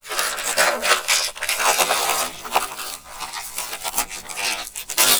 MONSTERS_CREATURES
ALIEN_Communication_13_mono.wav